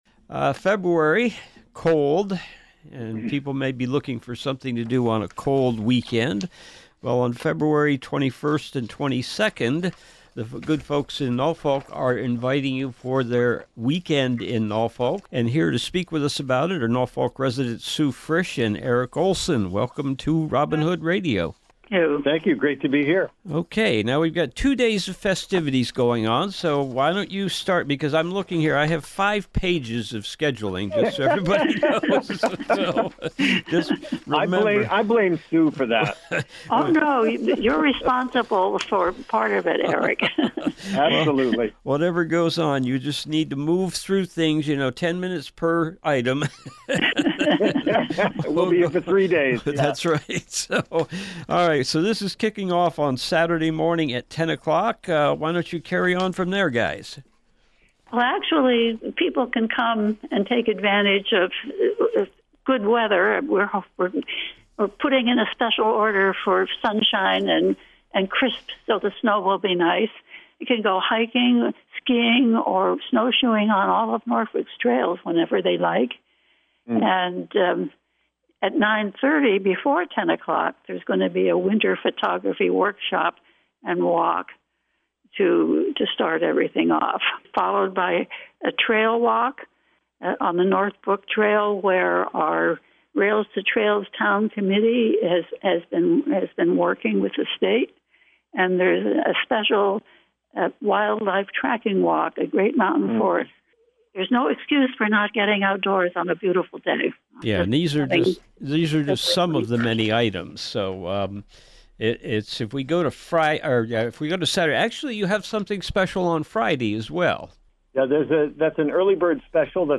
ROBIN HOOD RADIO INTERVIEWS Interview